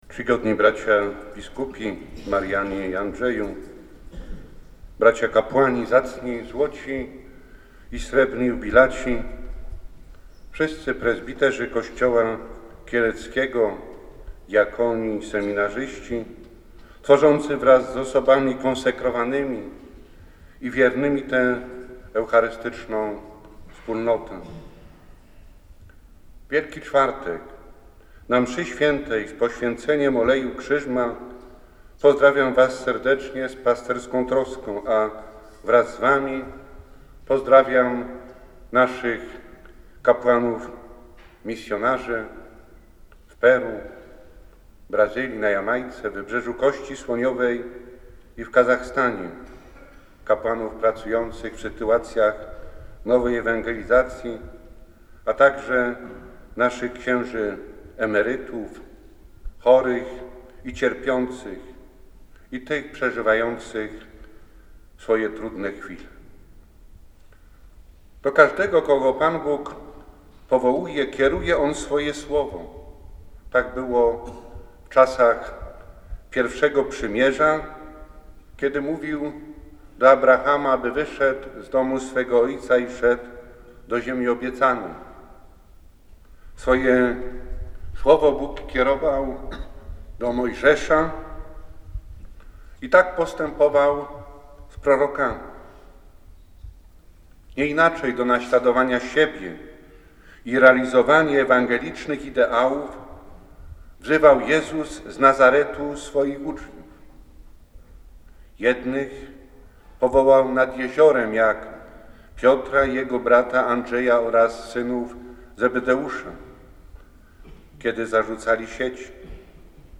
Biskup Kielecki w wygłoszonej homilii mówił, że to święto duchownych a także czas refleksji nad darem powołania i realizacją misji jaką powierzył im Kościół.
Homilia ks. bpa Jana Piotrowskiego